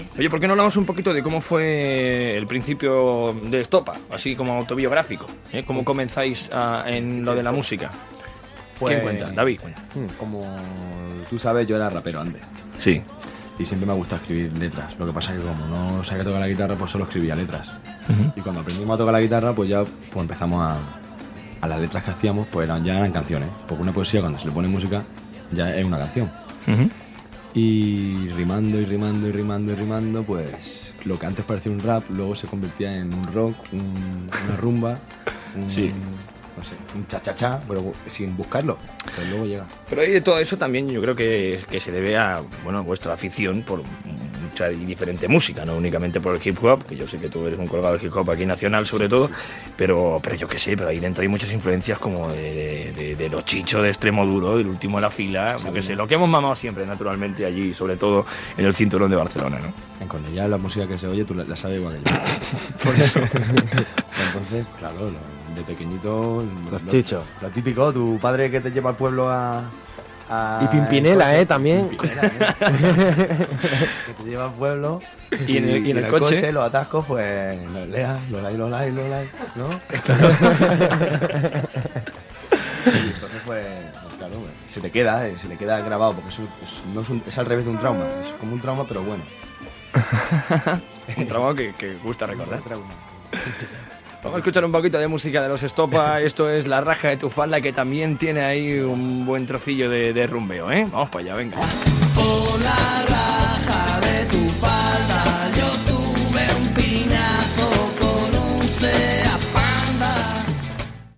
Radio show
Primera entrevista a la cadena 40 Principales als germans David i Jose Muñoz del grup Estopa que presenten el seu primer disc